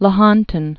(lə-hŏntən), Lake